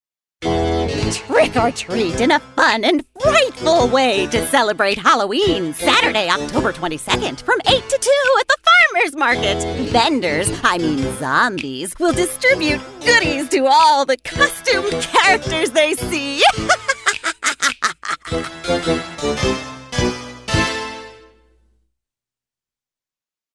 Deep, Sincere, Energetic: A rich voice who is persuasively authoritative.